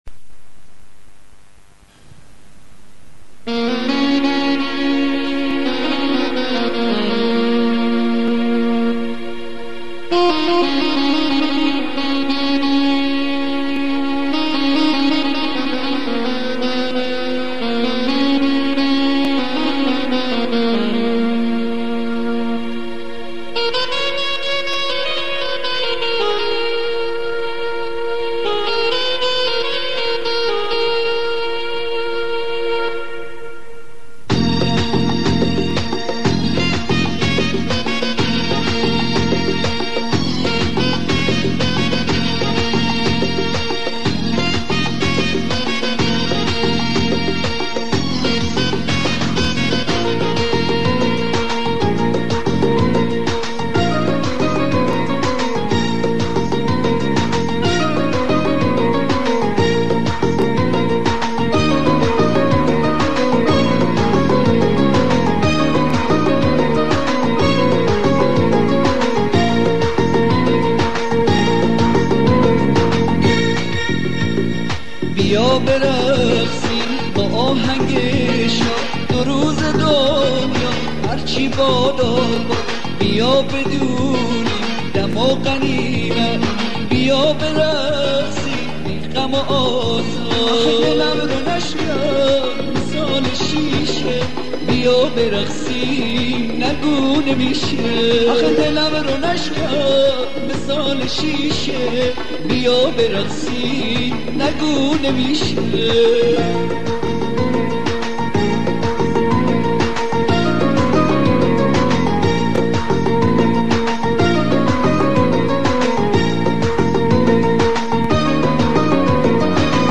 آهنگ شاد
بندری کوچه بازاری
آهنگ قدیمی